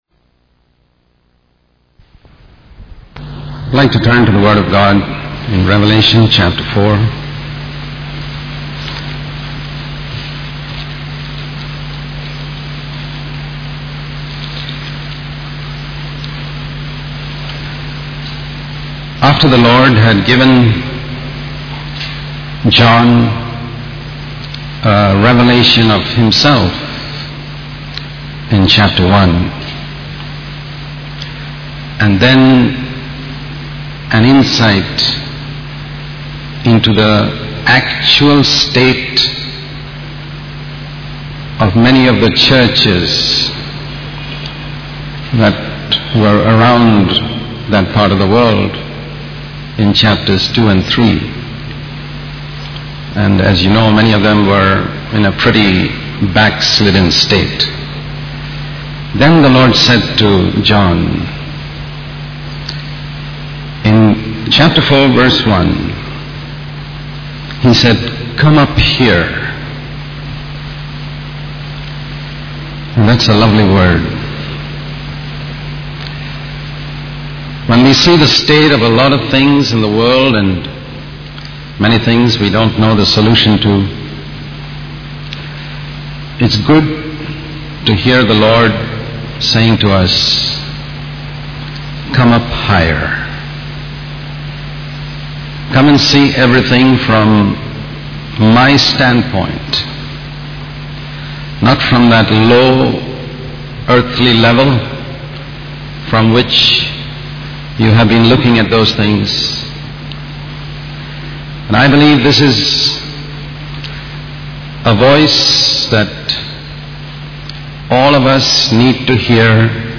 In this sermon, the speaker focuses on Revelation chapter 4 and the invitation given to John to 'come up higher' and see things from God's perspective. The speaker emphasizes the importance of seeking fellowship with God before going out to serve Him.